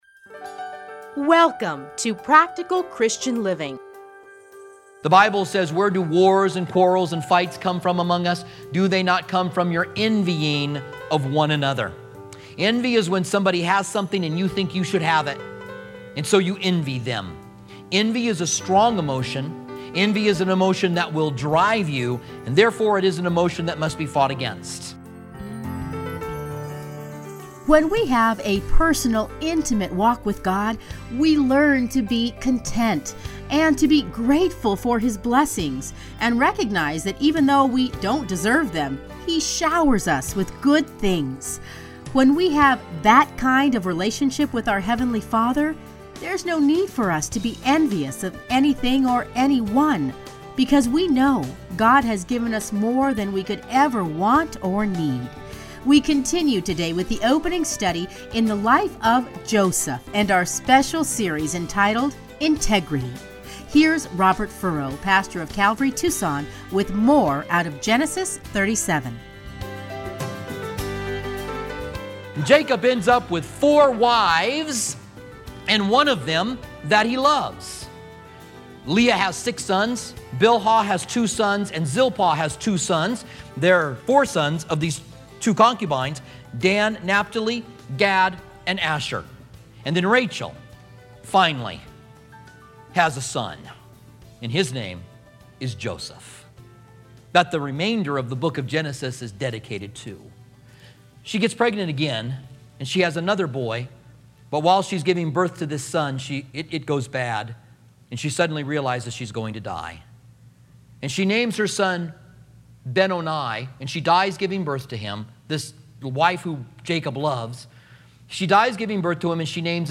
teachings are edited into 30-minute radio programs titled Practical Christian Living